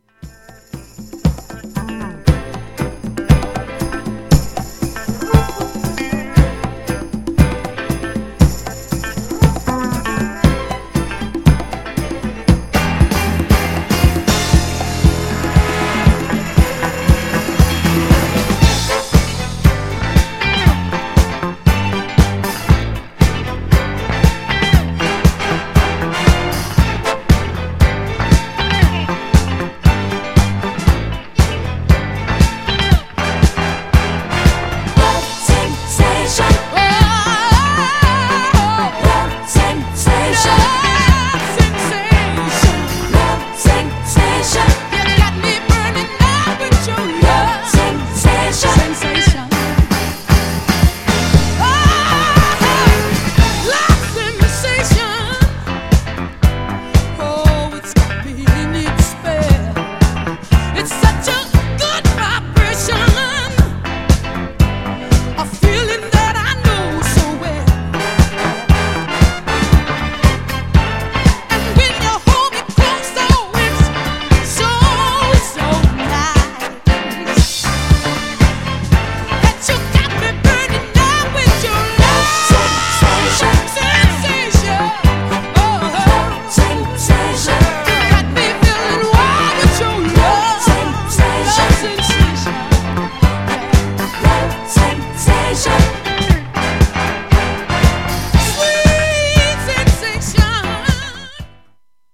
ディスコ
彼女の圧倒的な歌唱力と、疾走感のあるサウンドは、サンプリングネタとして多用されました。